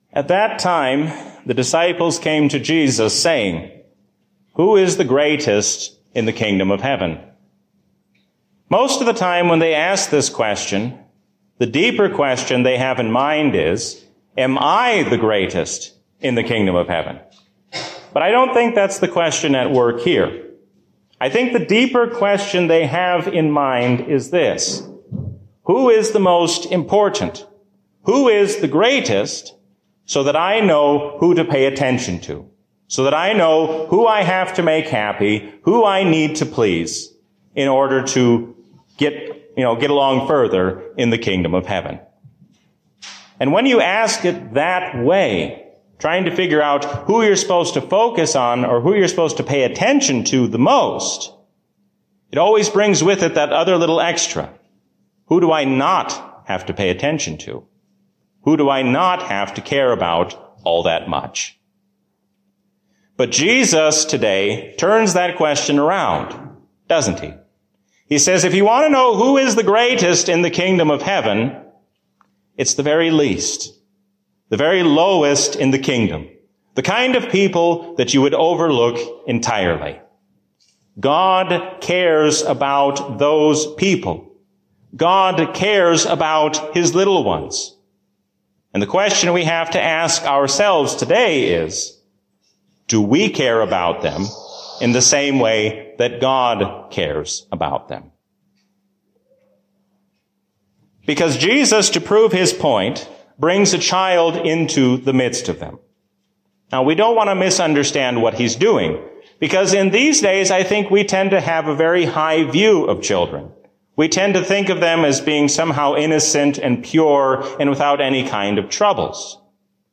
Sermon
A sermon from the season "Trinity 2024." There can be no true Christian love without holding on firmly to Biblical truth.